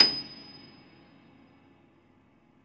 53c-pno27-D6.wav